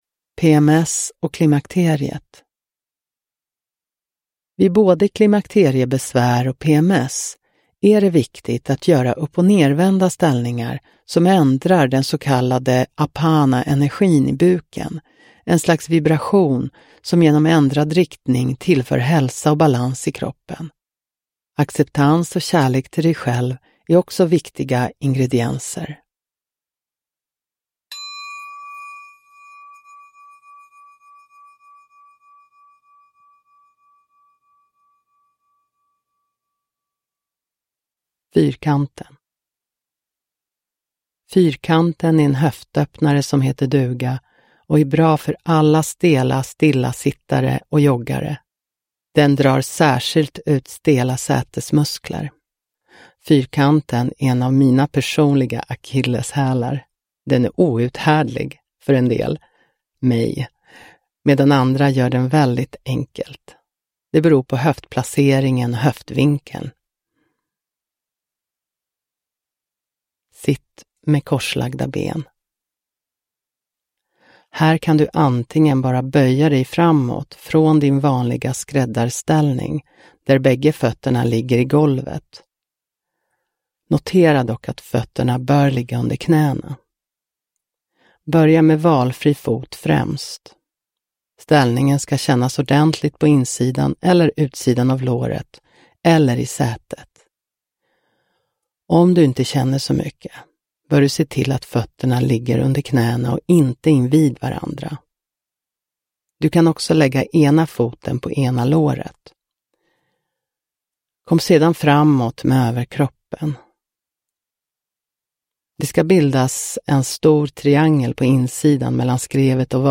10 övningar 35–55 minuter